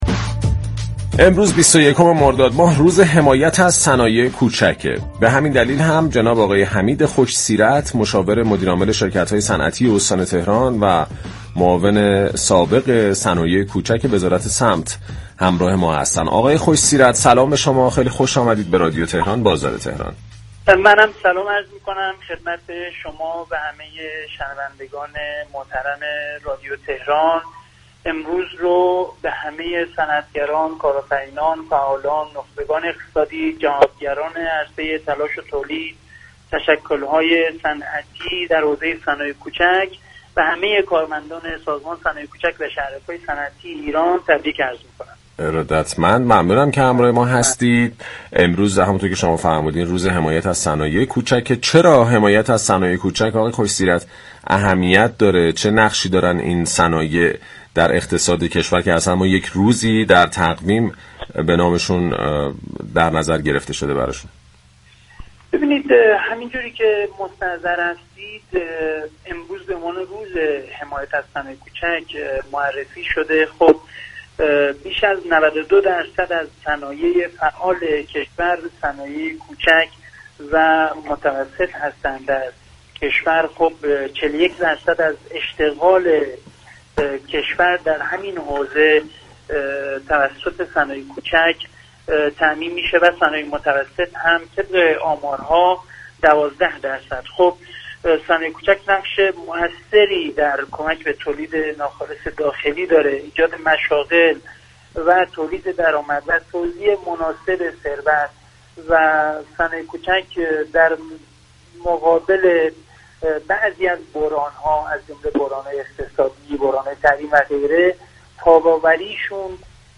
به گزارش پایگاه اطلاع رسانی رادیو تهران، حمید خوش سیرت مشاور مدیرعامل شركت‌های صنعتی استان تهران و معاون سابق صنایع كوچك وزارت صمت در گفت و گو با «بازار تهران» اظهار داشت: بیش از 92 درصد صنایع فعال كشور، صنایع كوچك و متوسط هستند و 41 درصد اشتغال كشور از طریق صنایع كوچك و 12 درصد آن از طریق صنایع متوسط محقق می‌شود.